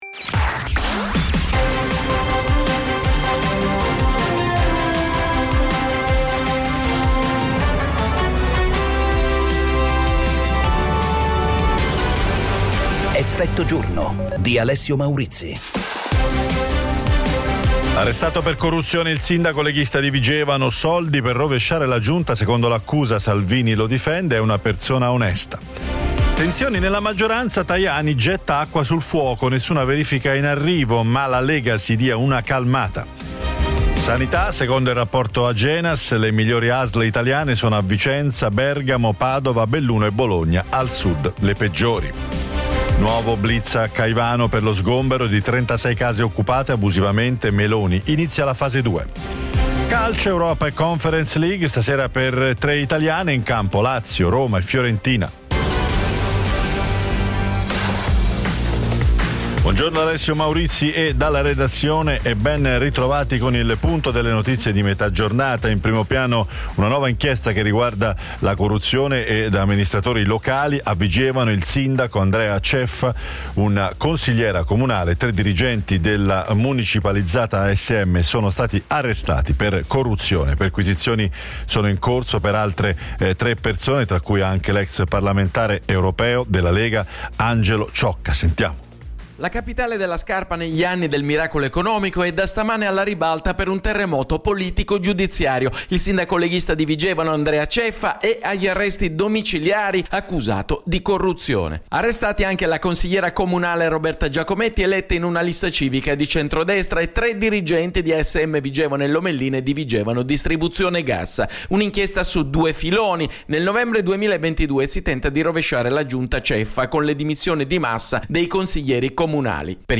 Effetto giorno è la trasmissione quotidiana che getta lo sguardo oltre le notizie, con analisi e commenti per capire ed approfondire l'attualità attraverso ospiti in diretta e interviste: politica, economia, attualità internazionale e cronaca italiana.